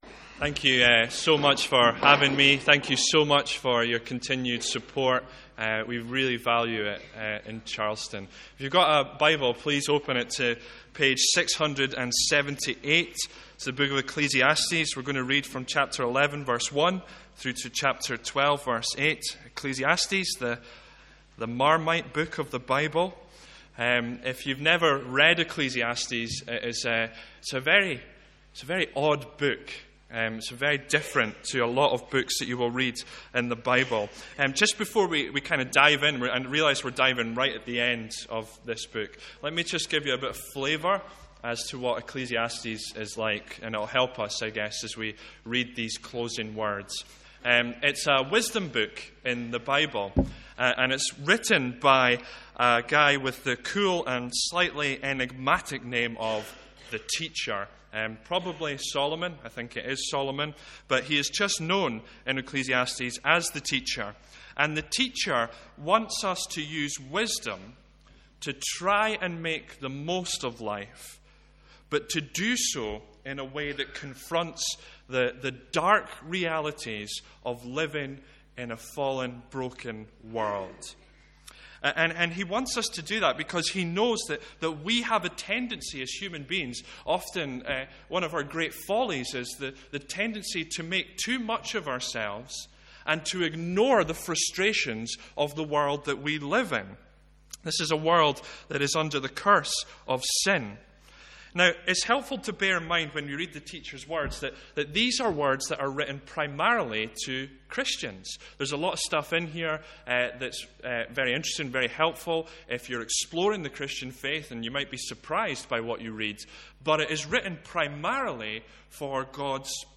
From our morning service.